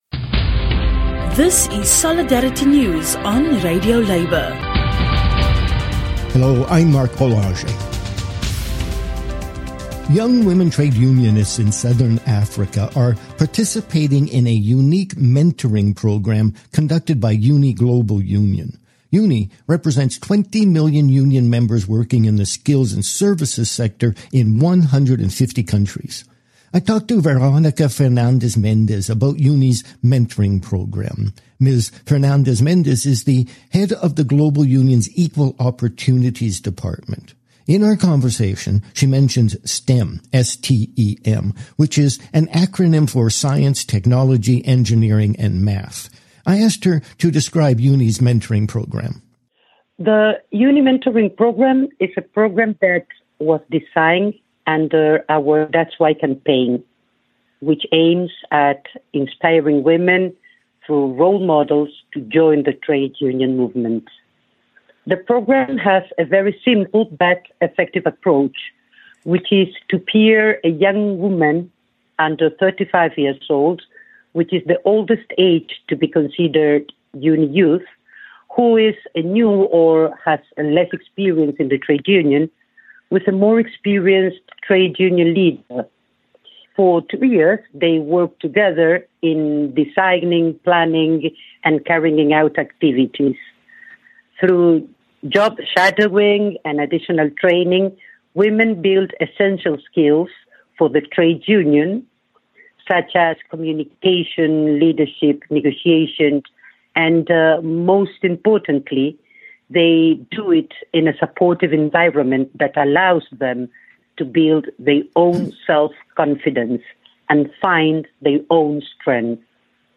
UNI Global Union has a unique programme to mentor young women unionists as they strive to become labour leaders. An interview